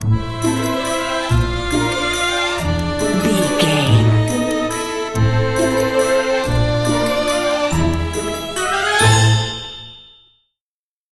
Ionian/Major
orchestra
strings
flute
drums
violin
circus
goofy
comical
cheerful
perky
Light hearted
quirky